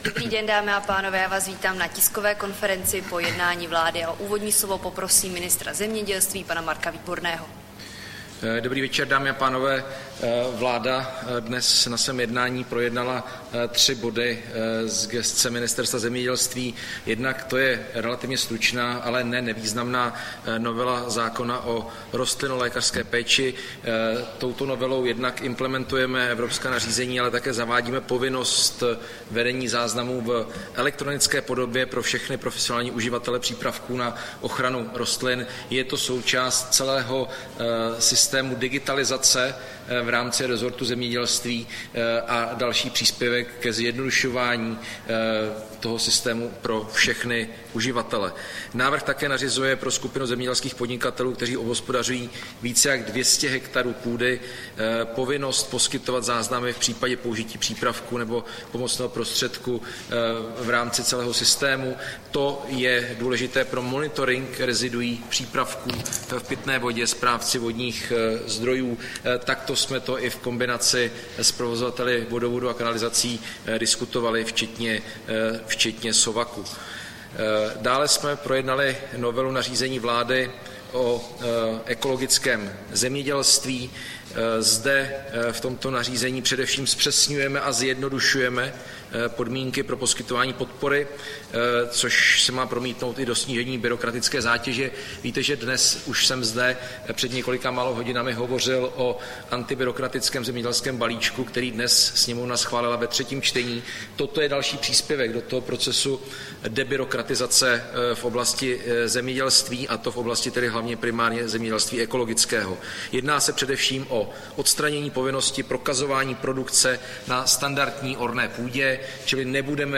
Tisková konference po jednání vlády, 22. ledna 2025